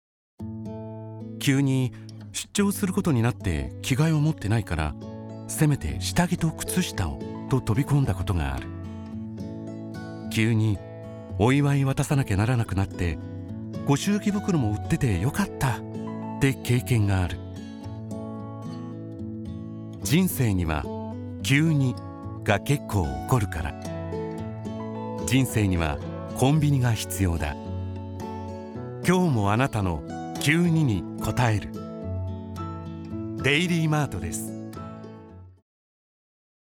My voice is elegant and calmness, and I have extensive experience in “company introductions,” “product introductions,” and “documentaries.
Dignified
Calm